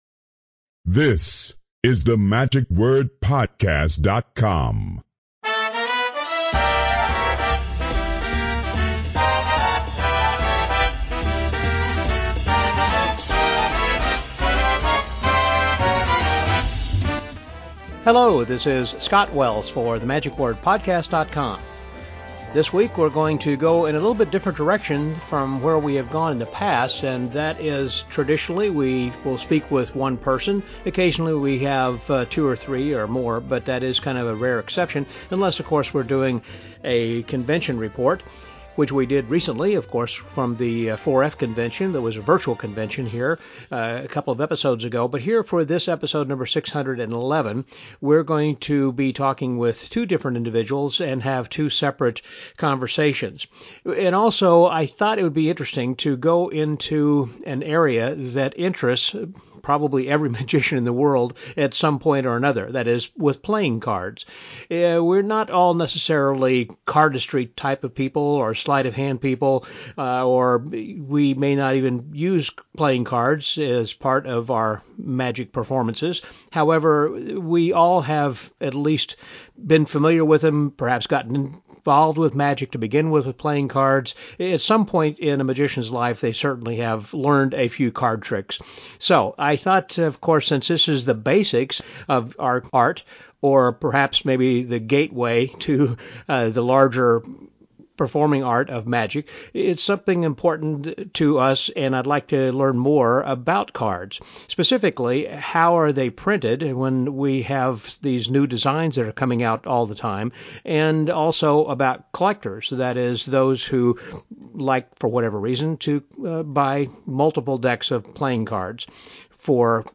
Why are magicians fascinated by playing cards? This week week have two separate conversations with two diverse people (one a creator and the other a collector) from two sides of the planet.